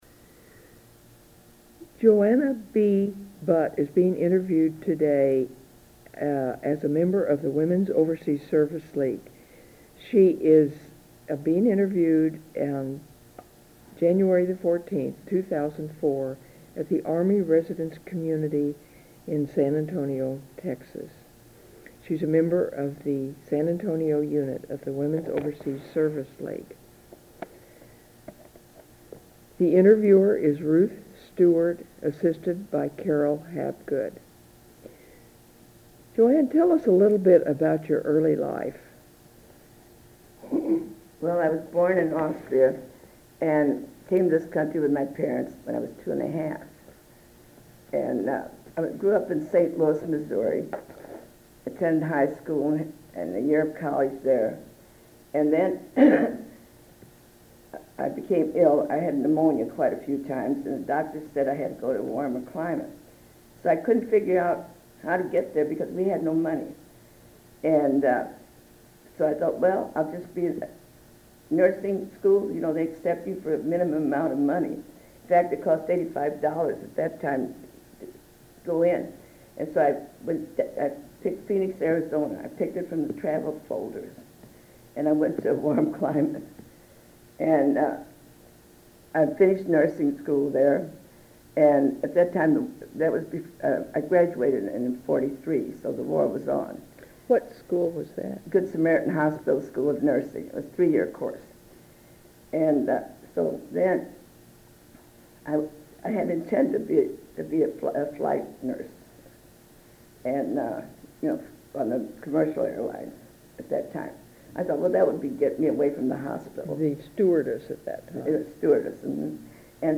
Women's Overseas Service League Oral History Project